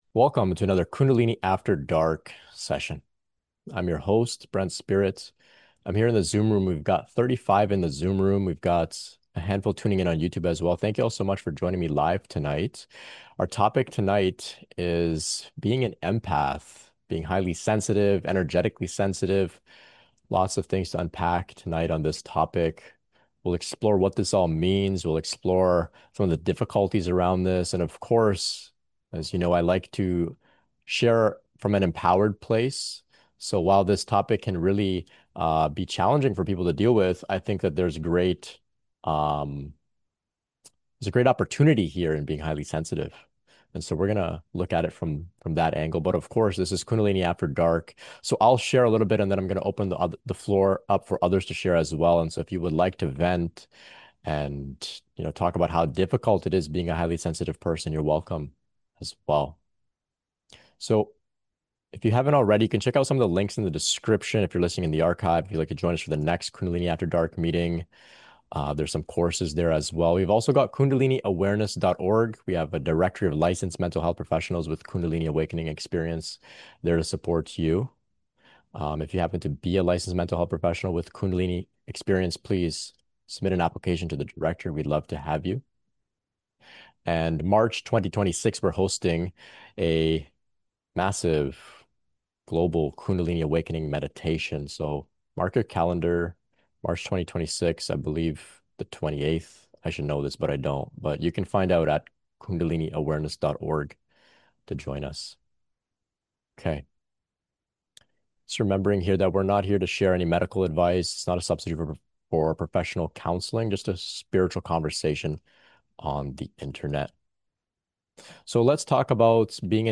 Recording of a laid back interactive conversation on Kundalini Shakti awakening, energetic sensitivity, empathy, and how to manage being a highly sensitive person.